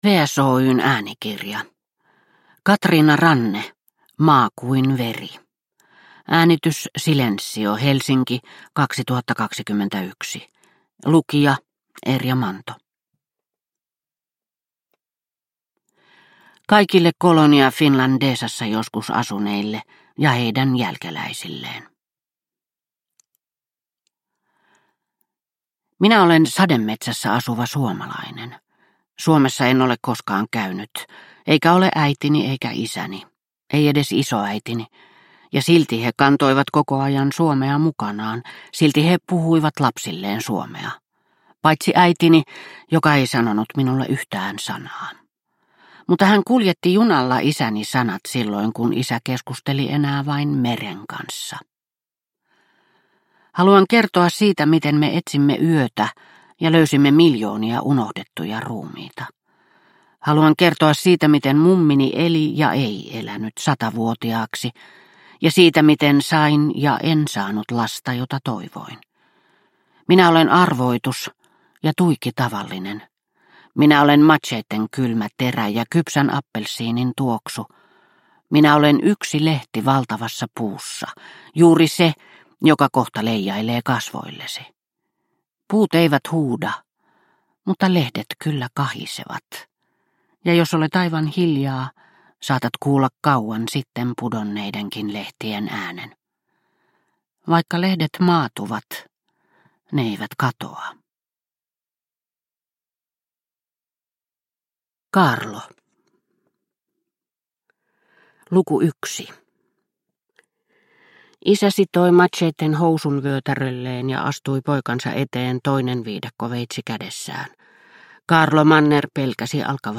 Maa kuin veri – Ljudbok – Laddas ner